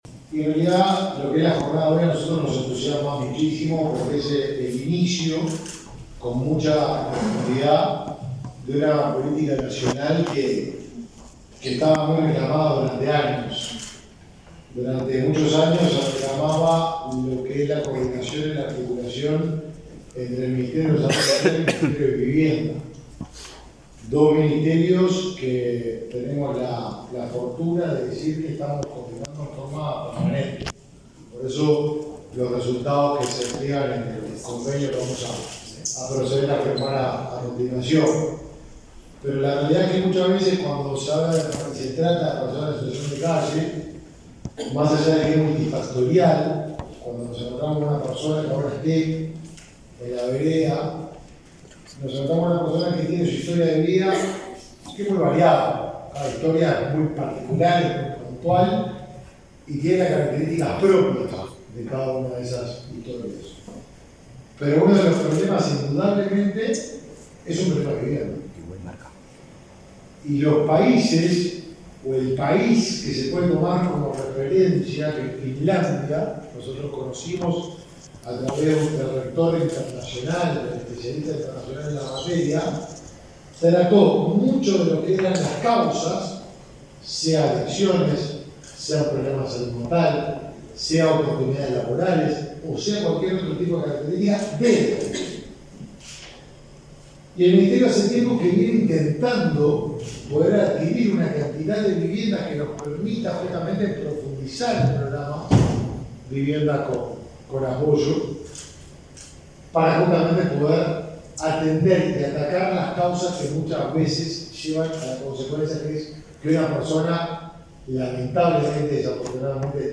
Palabra de autoridades en convenio entre el Mides y el MVOT